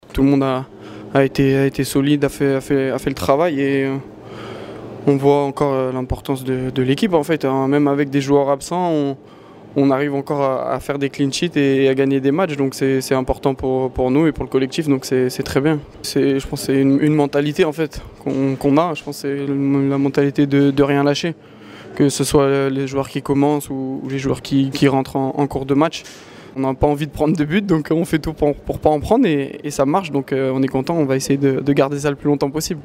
Au micro du club, le défenseur Melvin Bard fait part de sa satisfaction.
son-melvin-bard-defenseur-ogc-nice-17514.mp3